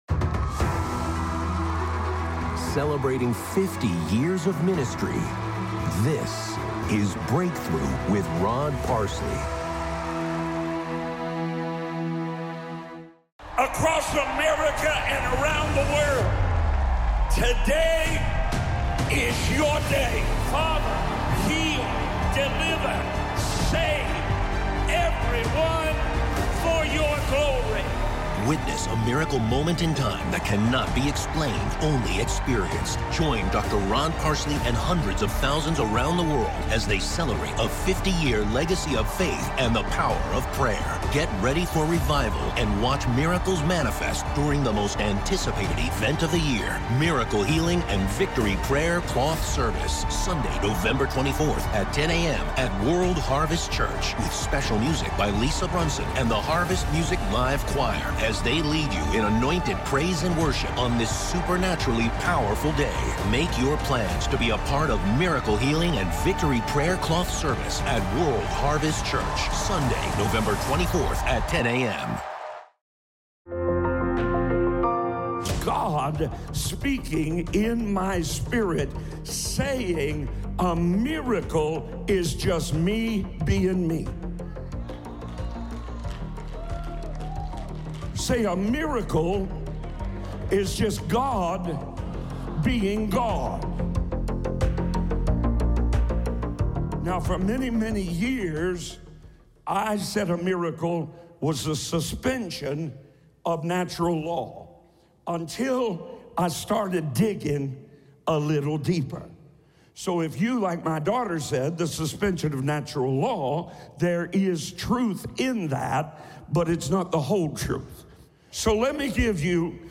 Audio only from the daily television program Breakthrough